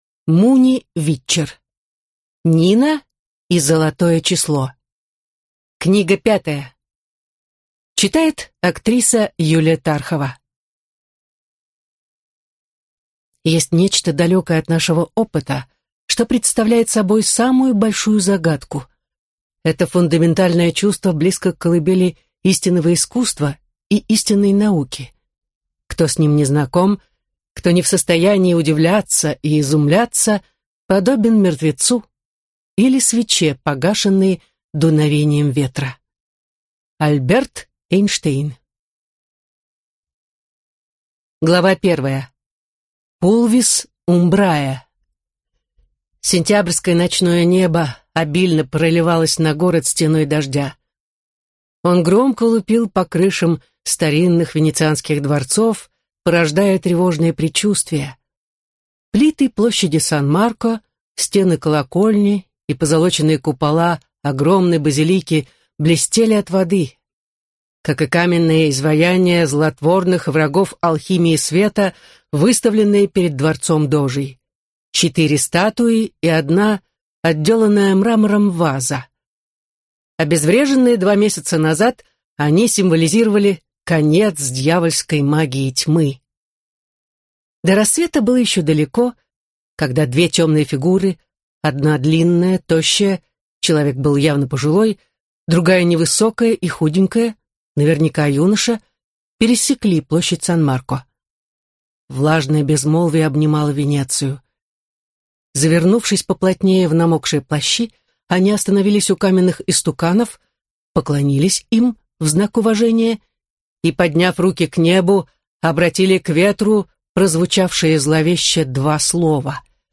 Аудиокнига Нина и Золотое Число | Библиотека аудиокниг